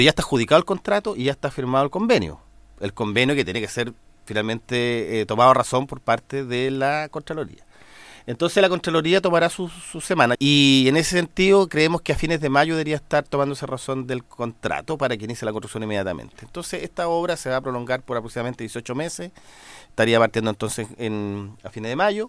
En conversación con Radio Bío Bío, el delegado Presidencial, Jorge Alvial, abordó el estado de avance de una serie de proyectos que han sufrido postergaciones en la capital regional.